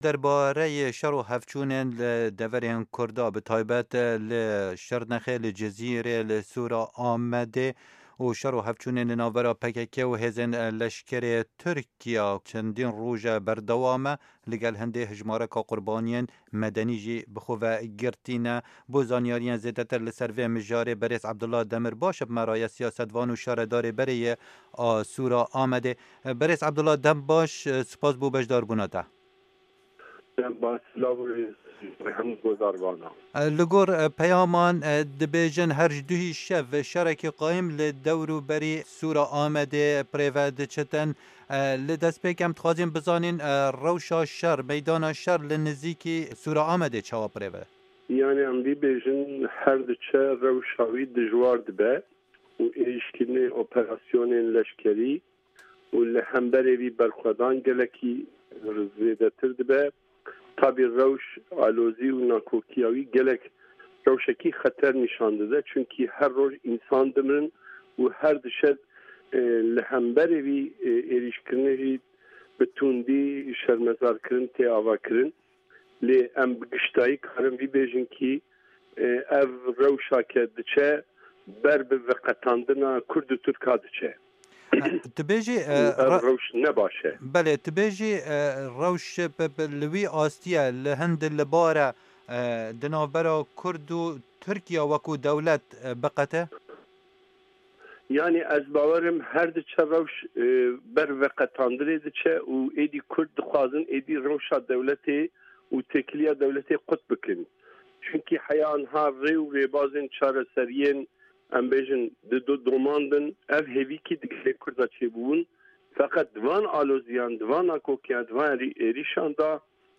Hevpeyvin digel Abdullah Demîrbaş